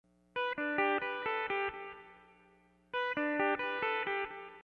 Zumsteel single-neck 12 string steel guitar
Single String Pickin'
Lick # 1 is in the key of G.